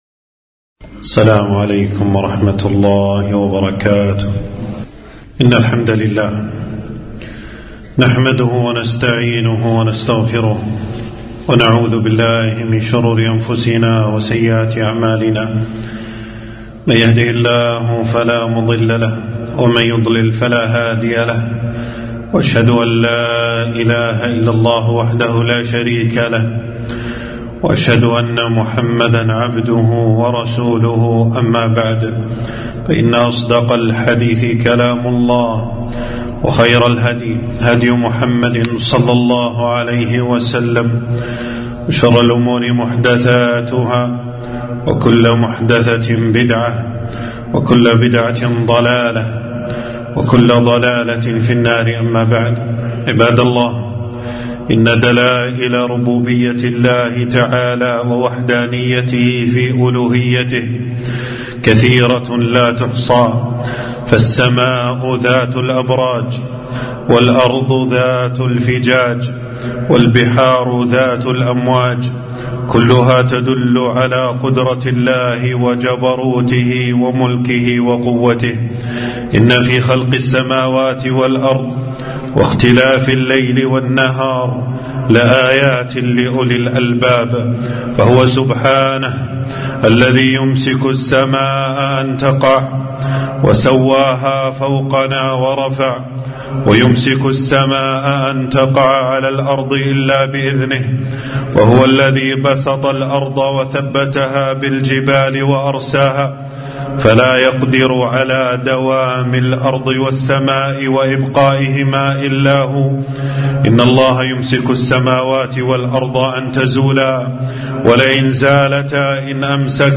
خطبة - الزلازل من آيات الله يخوف بها عباده